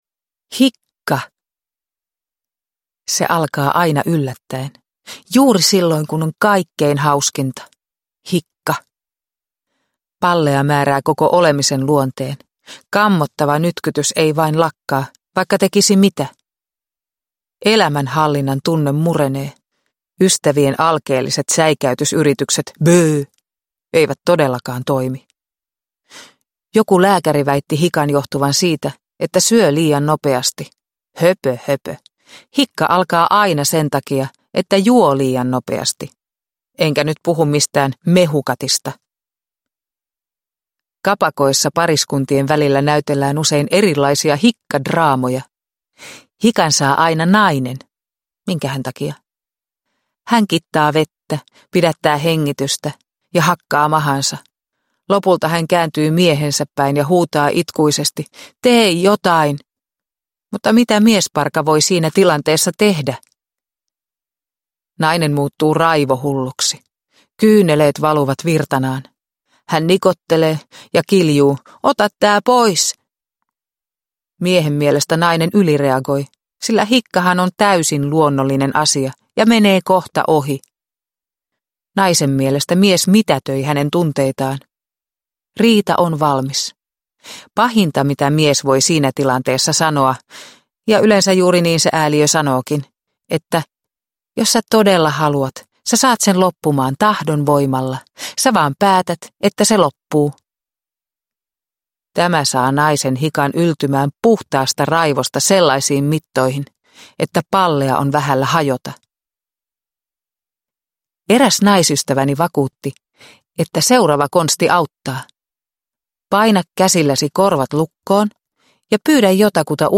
Laskeva neitsyt ja muita kirjoituksia – Ljudbok – Laddas ner